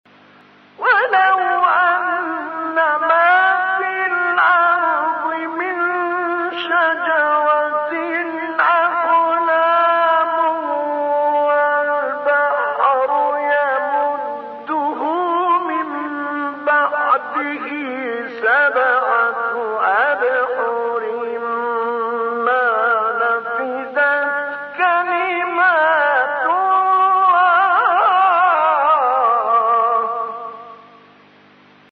مقطعی زیبا از سوره لقمان با صدای طه الفشنی | نغمات قرآن | دانلود تلاوت قرآن